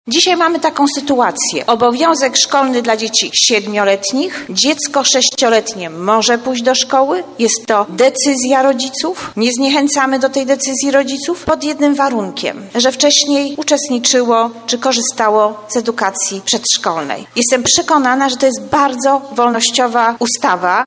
Przywrócenie obowiązku szkolnego dla dzieci w wieku od 7 lat to odpowiedź na opinie Polaków, mówi Marzena Machałek Posłanka na Sejm RP.